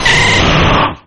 Archivo:Grito de Venusaur.ogg